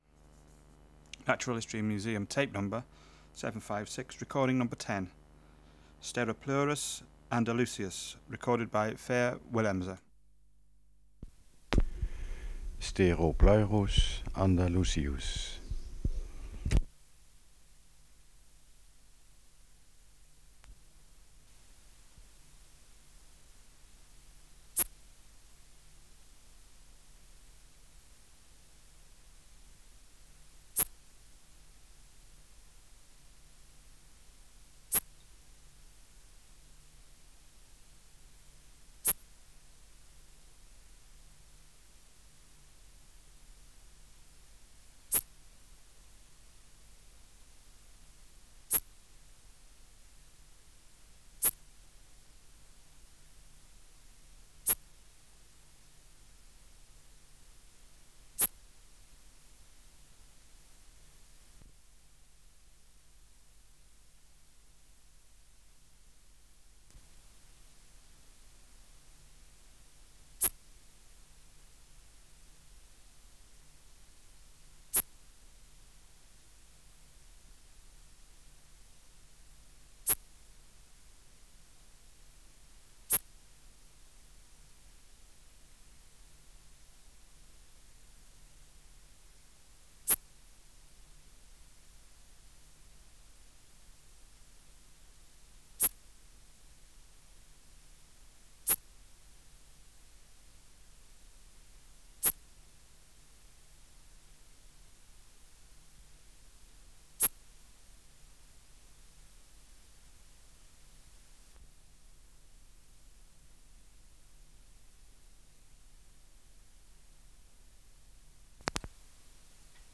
588:10 Steropleurus andalusius (756r10) | BioAcoustica
Air Movement: Nil Substrate/Cage: In cage Biotic Factors / Experimental Conditions: Isolated male
Microphone & Power Supply: AKG D202E (LF circuit off) Distance from Subject (cm): 4